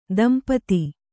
♪ dampati